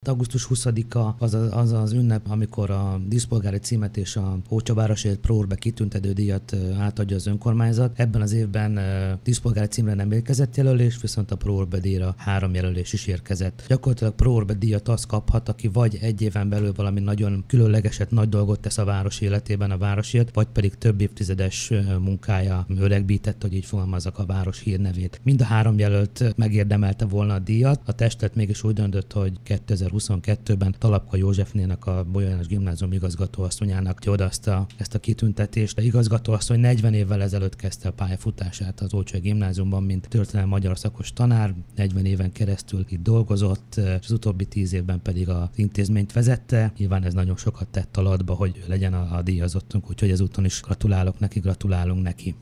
Bukodi Károly polgármestert hallják.